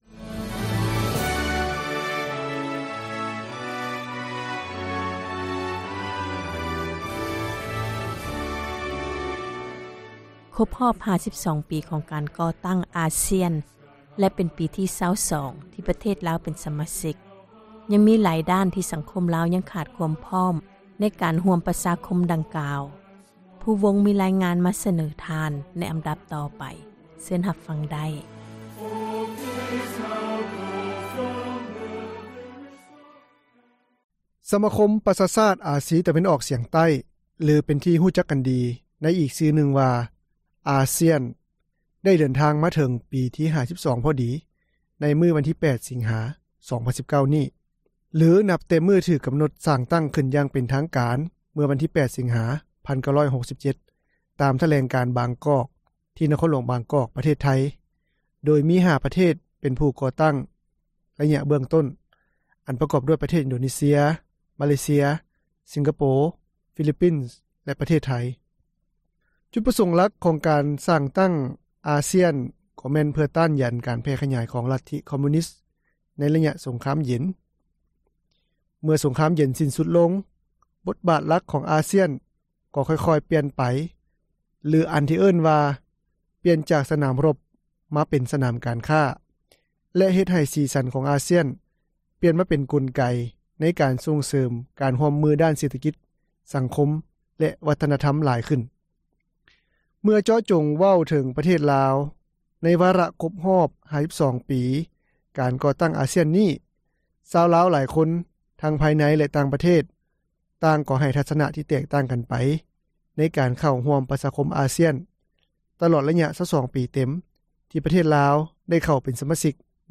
ນັກສຶກສານາງນຶ່ງ ຈາກມຫາວິທຍາລັຍແຫ່ງຊາຕລາວ ໄດ້ແບ່ງປັນທັສນະຂອງນາງຕໍ່ຜູ້ສື່ຂ່າວວິທຍຸເອເຊັຽເສຣີ ໃນມື້ຄົບຮອບ 52 ປີການກໍ່ຕັ້ງອ່າຊ້ຽນນີ້ວ່າ ນາງເຫັນວ່າ ການເຂົ້າຮ່ວມເປັນສະມາຊິກອ່າຊ້ຽນຂອງລາວ ຖືວ່າມີຄວາມສຳຄັນໃນ ຫຼາຍດ້ານ ແລະ ດີກ່ອນ ທີ່ບໍ່ໄດ້ເຂົ້າ.
ນັກປັນຍາຊົນລາວໃນນະຄອນຫຼວງວຽງຈັນ ທ່ານນຶ່ງ ກໍໄດ້ສະທ້ອນຂໍ້ສັງເກດຂອງທ່ານ ຕໍ່ຜູ້ສື່ຂ່າວວິທຍຸເອເຊັຽເສຣີ ເມື່ອບໍ່ດົນມານີ້ເຊັ່ນດຽວ ກັນວ່າ ແຜນການພັທນາບຸກຄະລາກອນ ຂອງທາງການລາວ ເພື່ອໃຫ້ມີຄວາມພ້ອມຮັບມື ກັບໜ້າວຽກໃນໄລຍະ ໃໝ່ ຂອງອ່າຊ້ຽນ ຍັງຖື ເປັນສິ່ງທີ່ທ້າທາຍຫຼາຍ.